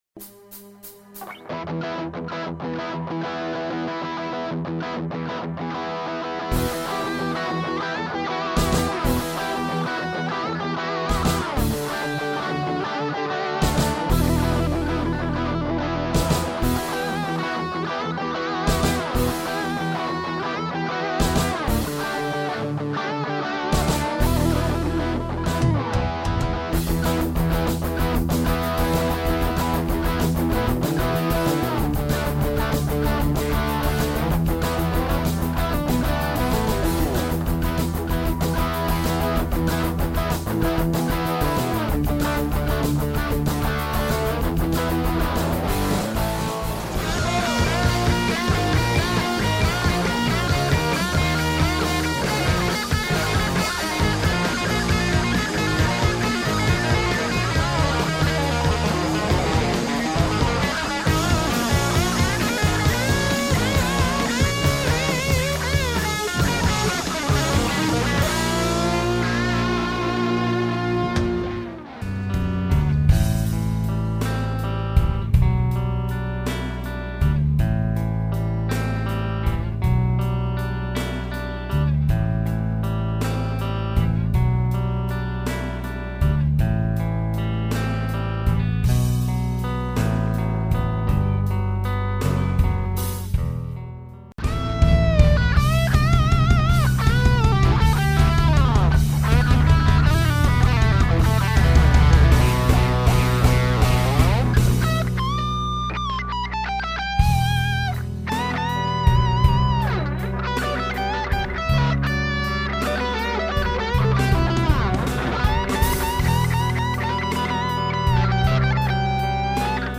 Inserito in un Cicognani Powerload per poter aumentare il volume a livello 5, leggermente spinto con un Digitech Tone Driver. Fender stratocaster 50 con Hot Rail al ponte.